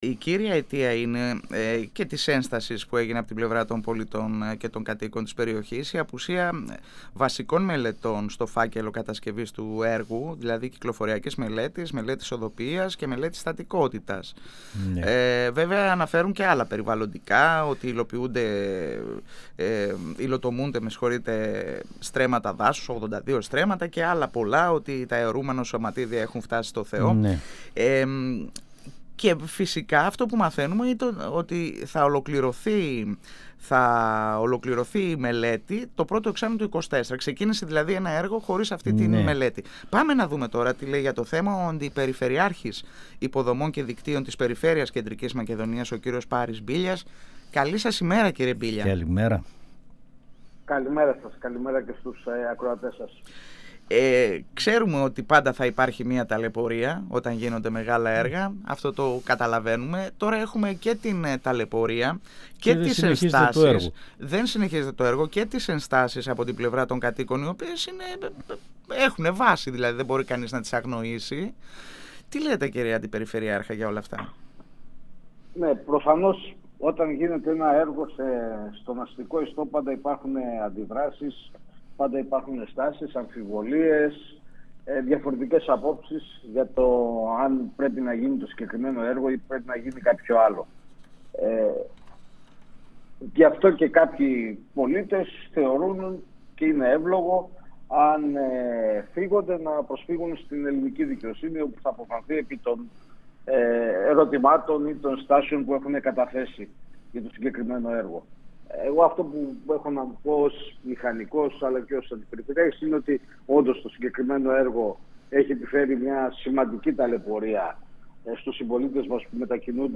Στις τελευταίες εξελίξεις στο έργο του Flyover αναφέρθηκε ο Αντιπεριφερειάρχης Υποδομών και Δικτύων Κεντρικής Μακεδονίας Πάρις Μπίλλιας μιλώντας στην εκπομπή « Εδώ και Τώρα» του 102FM της ΕΡΤ3: «Προφανώς όταν γίνεται ένα έργο στον αστικό ιστό, πάντα υπάρχουν αντιδράσεις, ενστάσεις, αμφιβολίες, διαφορετικές απόψεις.